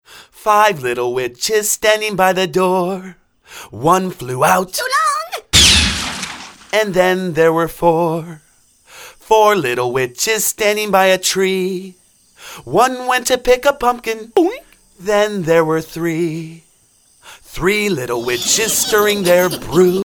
jazzy